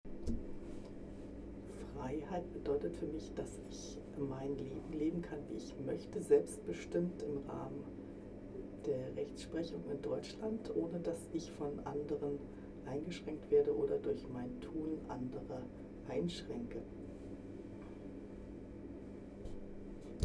Ein Fest für die Demokratie @ Bundeskanzleramt, Berlin
Standort war das Bundeskanzleramt. Der Anlass war Ein Fest für die Demokratie